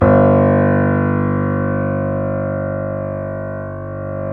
Index of /90_sSampleCDs/Roland L-CD701/KEY_YC7 Piano pp/KEY_pp YC7 Mono